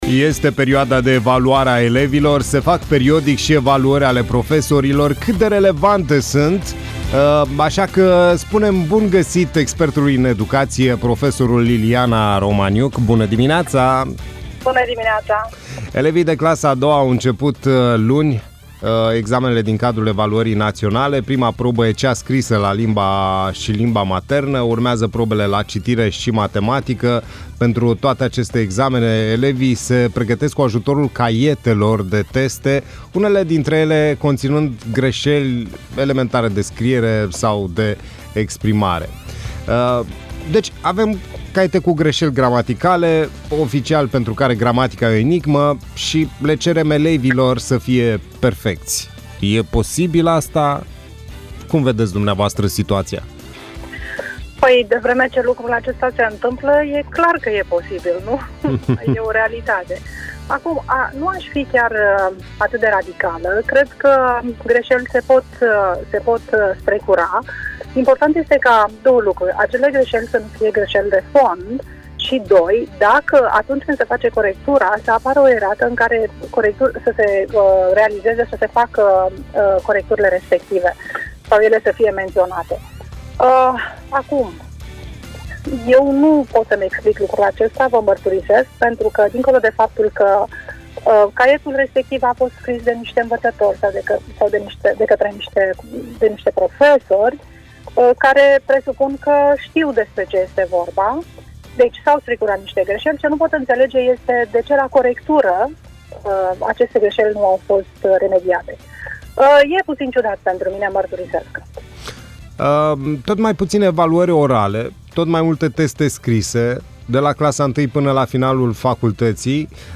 Cat de relevante sunt evaluarile elevilor si profesorilor? Interviu